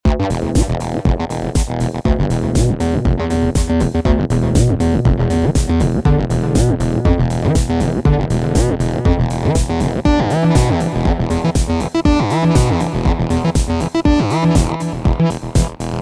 Enhance Your Bassline with Effects
Adding distortion, delay, and reverb helps shape the tone, making your bassline more aggressive, atmospheric, or expansive.